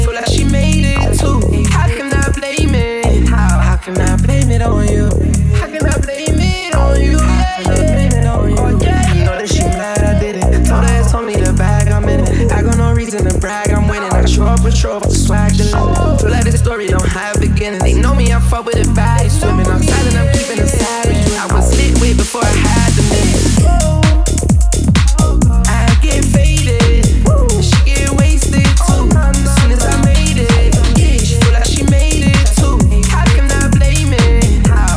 Hip-Hop Rap
Жанр: Хип-Хоп / Рэп